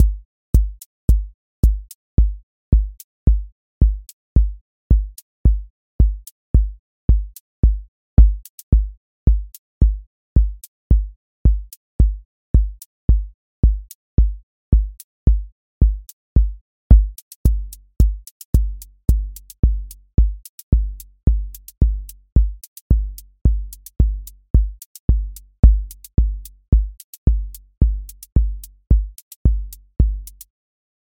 QA Listening Test house Template: four_on_floor
• macro_house_four_on_floor
• voice_kick_808
• voice_hat_rimshot
• voice_sub_pulse